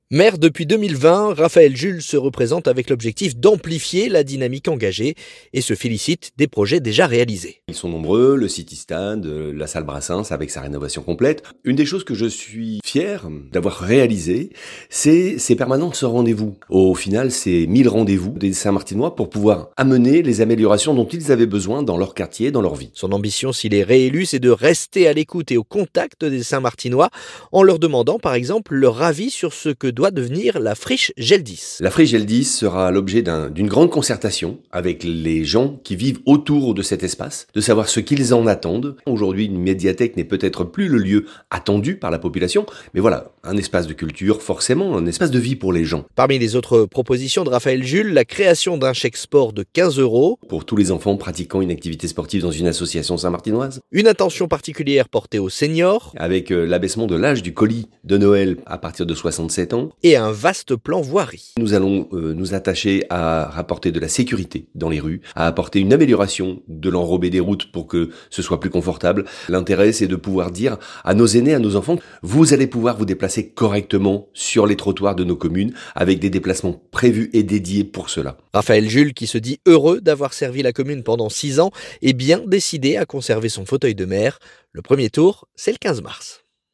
ENTRETIEN - A Saint-Martin-Boulogne, Raphaël Jules dévoile ses priorités pour « amplifier la dynamique »